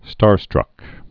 (stärstrŭk)